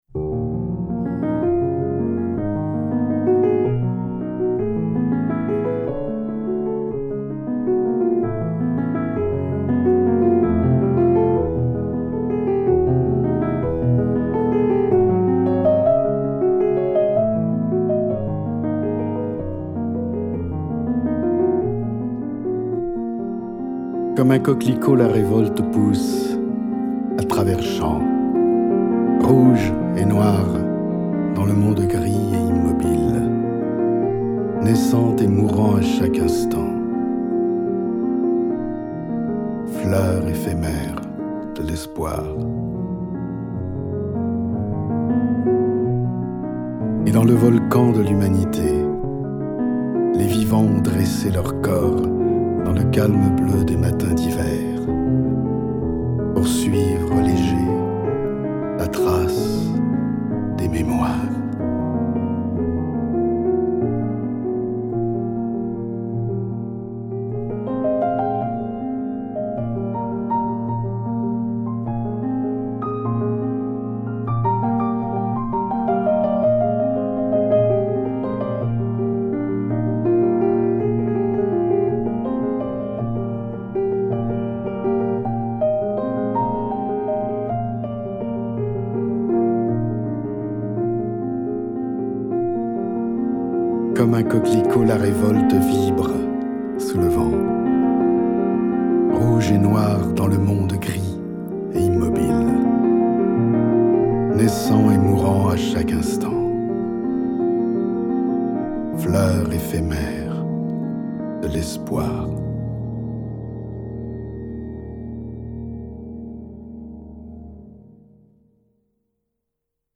Une chanson-poème